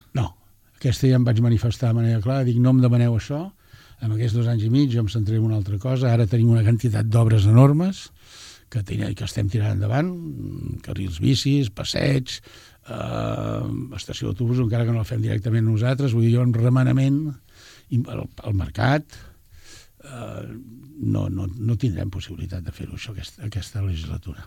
Tot plegat ho va explicar ahir el regidor d’ERC de Seguretat Ciutadana i Mobilitat, Antoni Bachiller a Ràdio Palamós. Explica que no es pot tirar endavant el projecte d’una nova comissaria ara per la quantitat d’obres que hi ha actualment al poble.